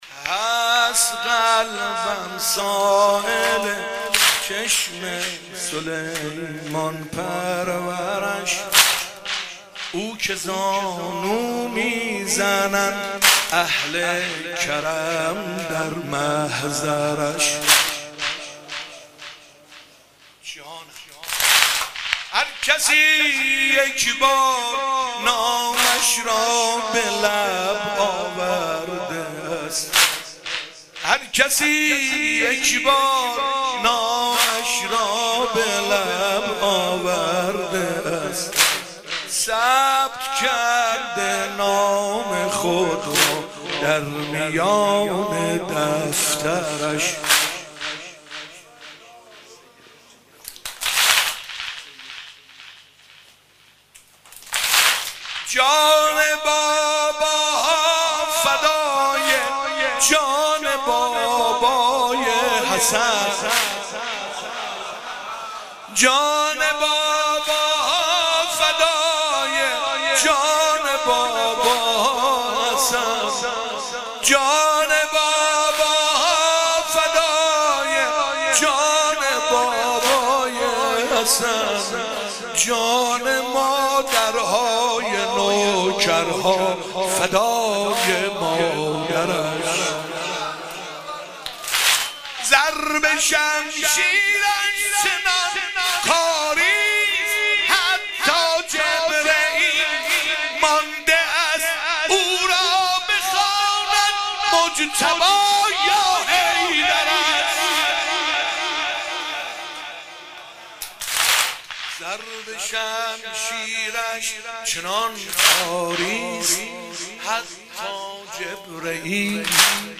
عقیق:حسینیه موج الحسین(ع)شب ششم محرم95
واحد شب ششم